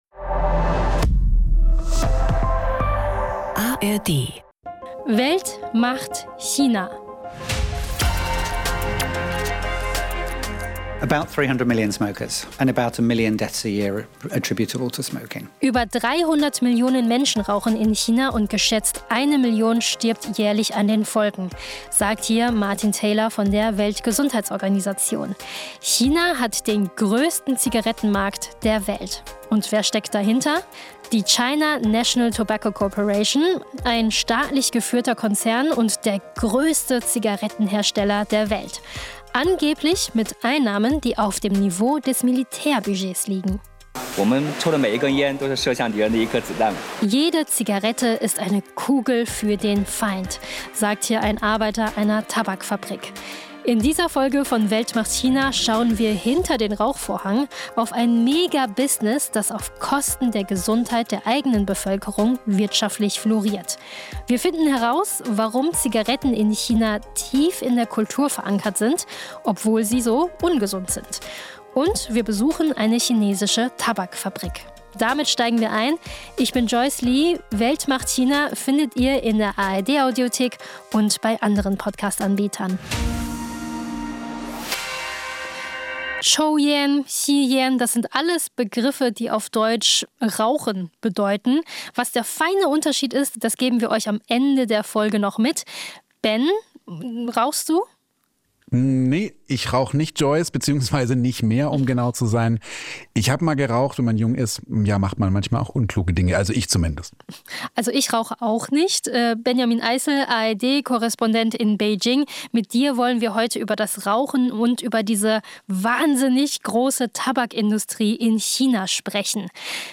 Aktuelle und ehemalige Korrespondent*innen und Expert*innen haben sich zusammengetan, um einen vielfältigen Einblick zu geben in das riesige Land.